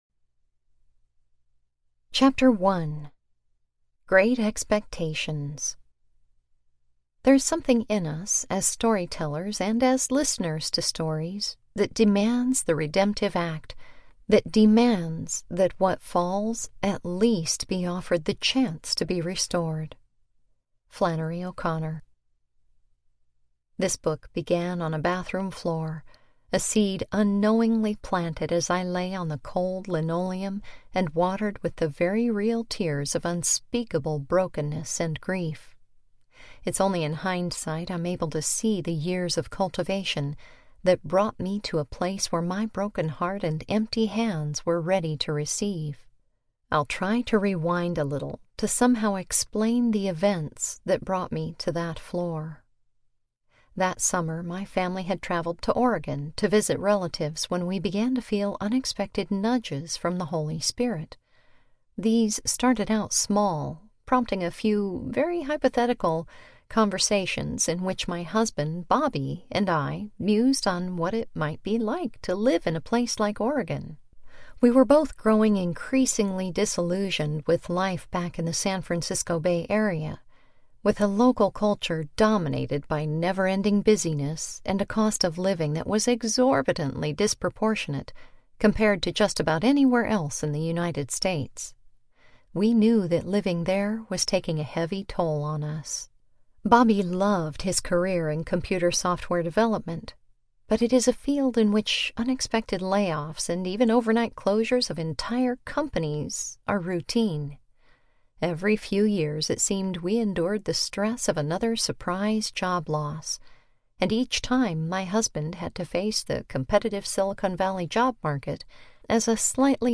The View from Rock Bottom Audiobook
Narrator
5.9 Hrs. – Unabridged